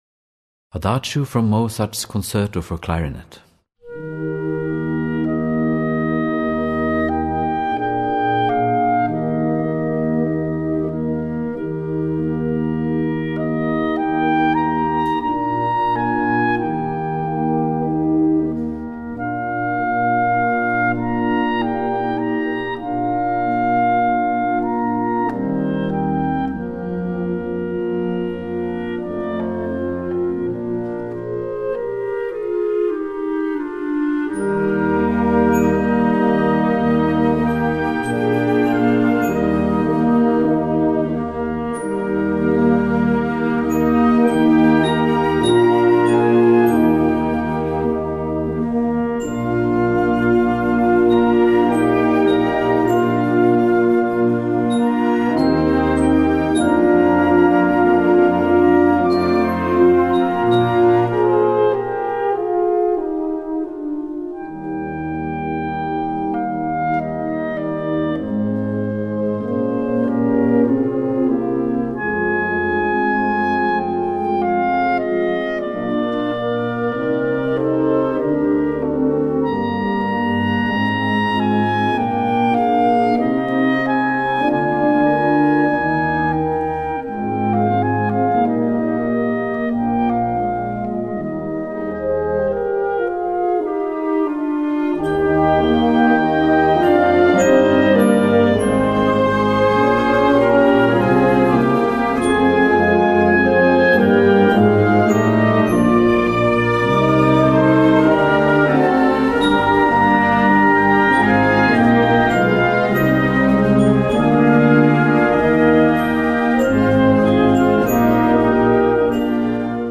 Gattung: Solostück für Klarinette
Besetzung: Blasorchester
Ab 7-stimmiger Besetzung spielbar.